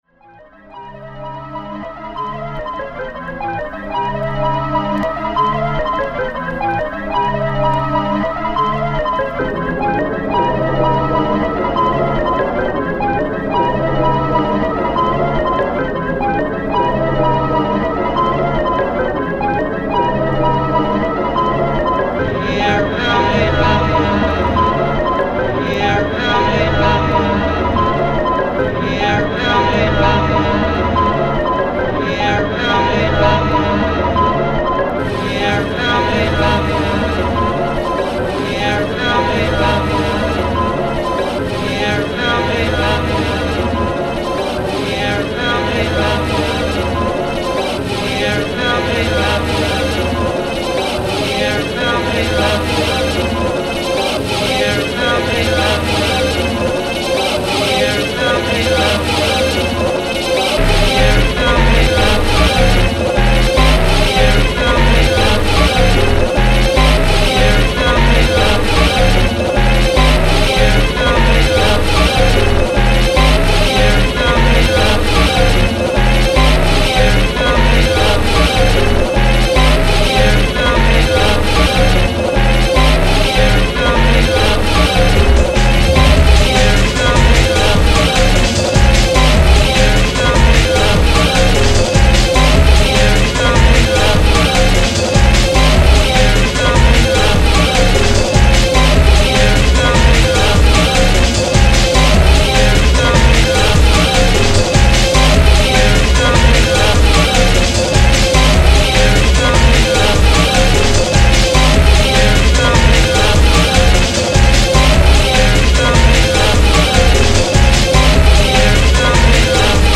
90s Jungle
intense and forward-driving